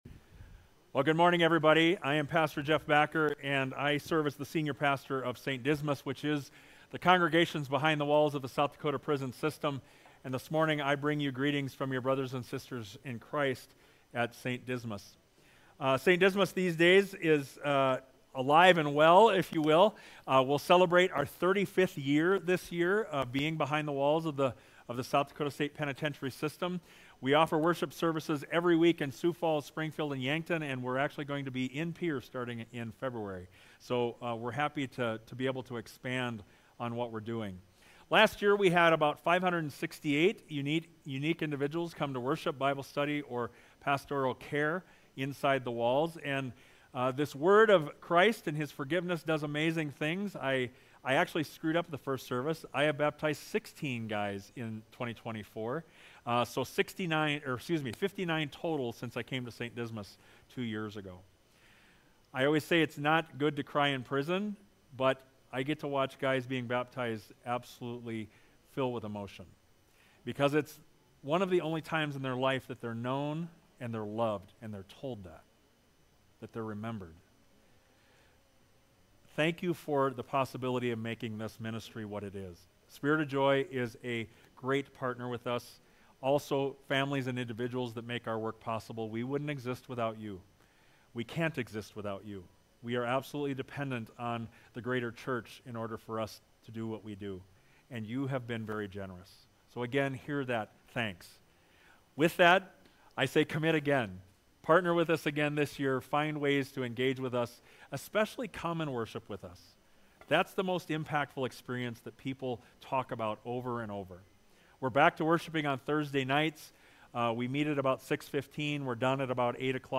1.5.25-Sermon.mp3